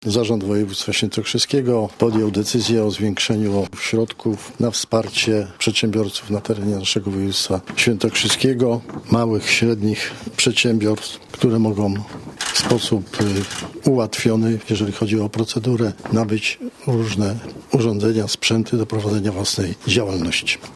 Mówi marszałek Andrzej Bętkowski: